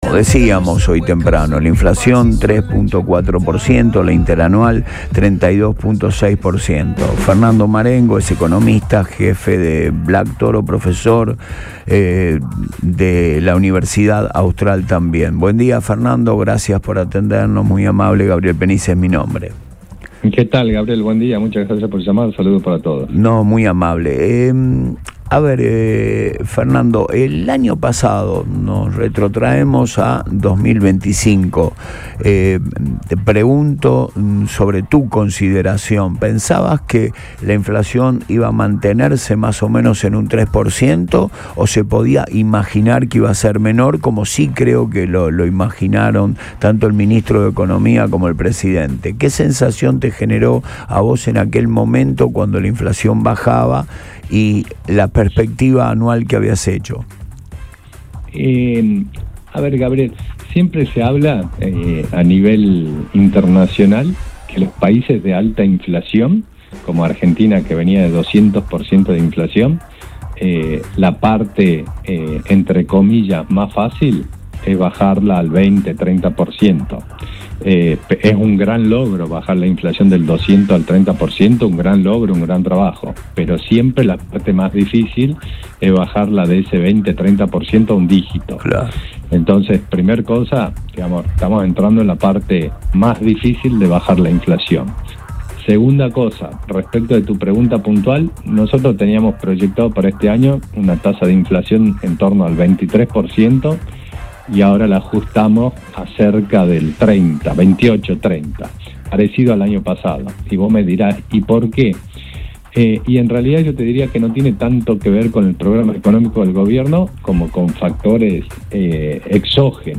El economista analizó en Antes de Todo el presente inflacionario, el impacto de factores externos y los desafíos estructurales del país.